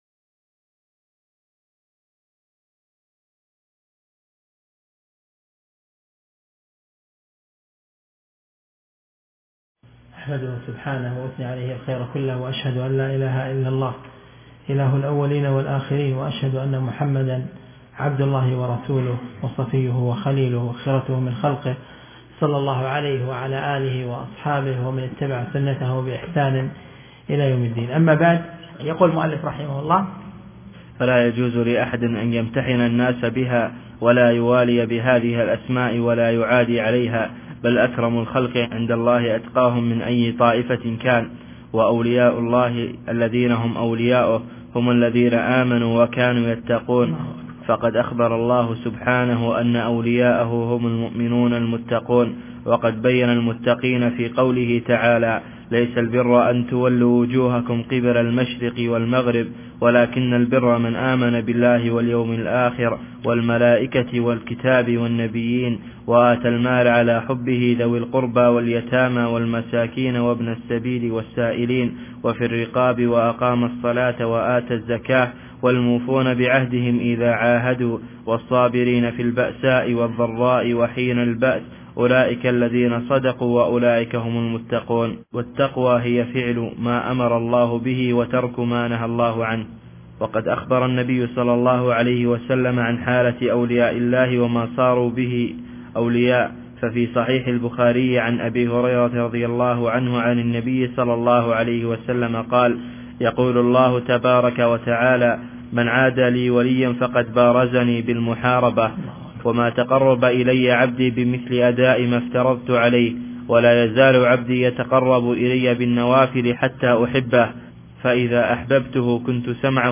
الدرس(12) من شرح رسالة الوصية الكبرى